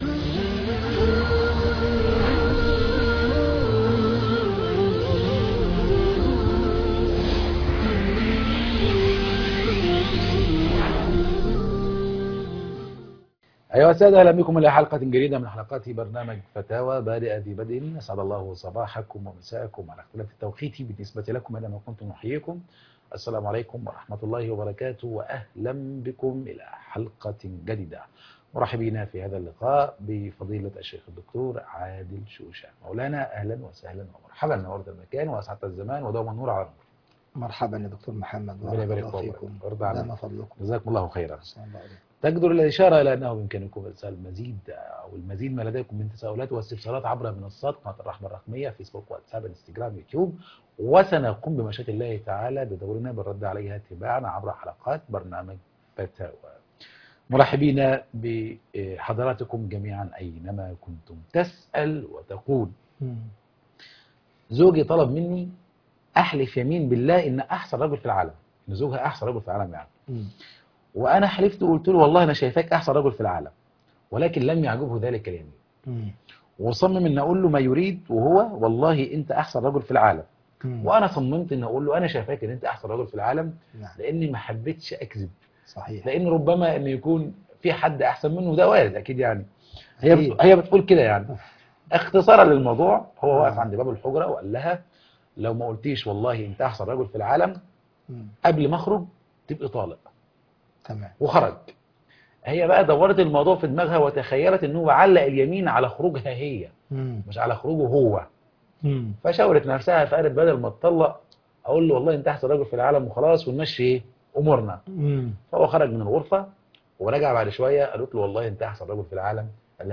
فتاوي